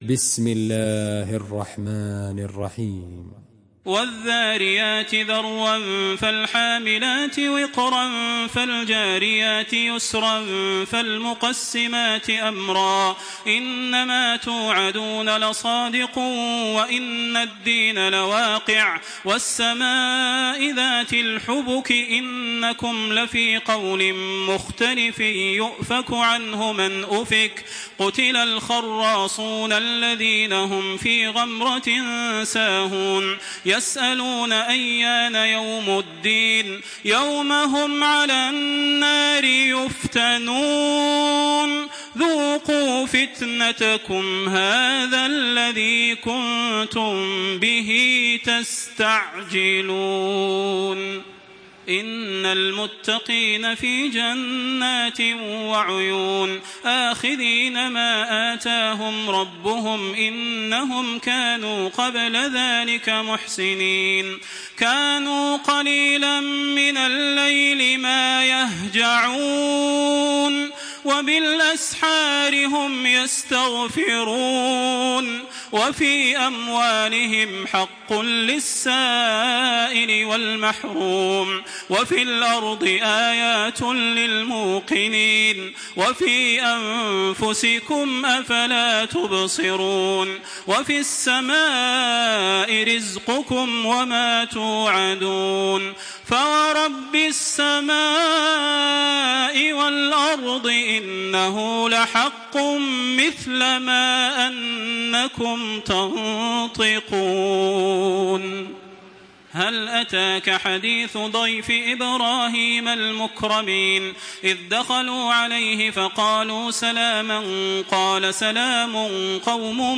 Surah Ad-Dariyat MP3 by Makkah Taraweeh 1426 in Hafs An Asim narration.
Murattal